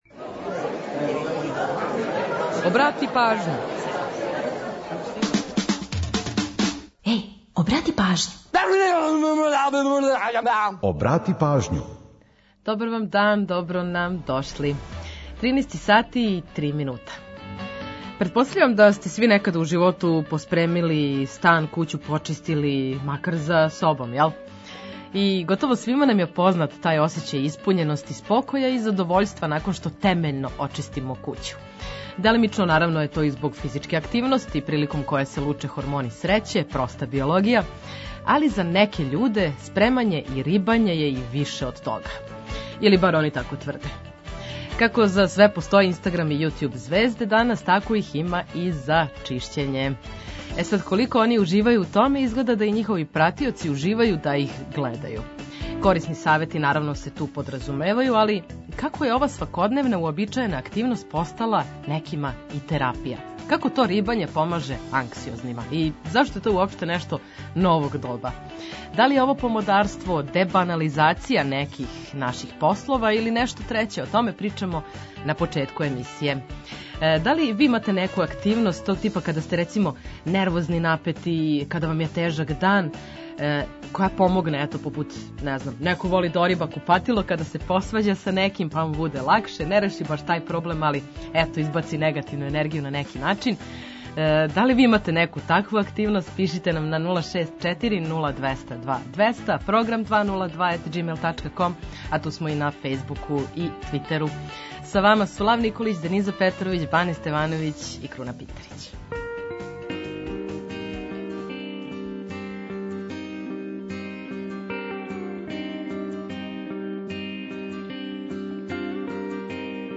Сервисне информације и наш репортер са подацима о саобраћају помоћи ће многима у организовању дана, а „Културни водич” је ту да предложи које манифестације широм Србије можете да посетите. Ту су и музичке теме којима подсећамо на приче иза песама, славимо рођенданe музичара, синглова и албума, а ту је и пола сата резервисаних само за нумере из Србије и региона.